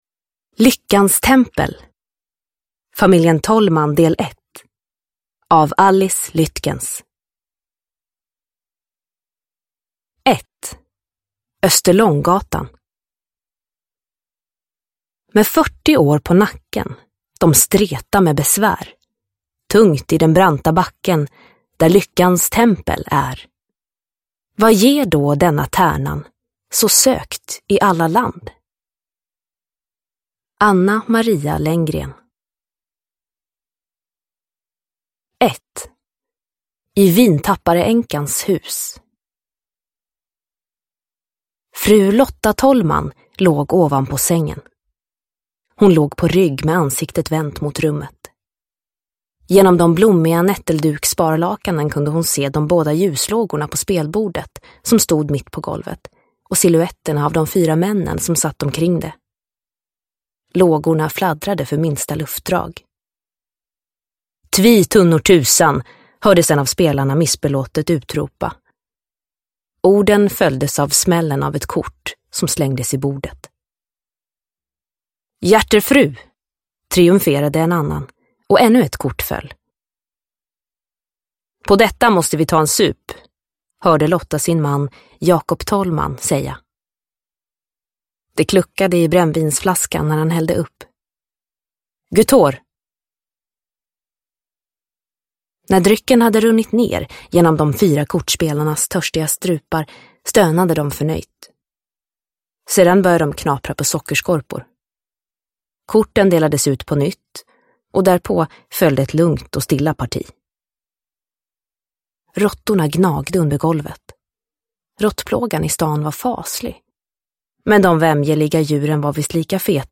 Lyckans tempel – Ljudbok – Laddas ner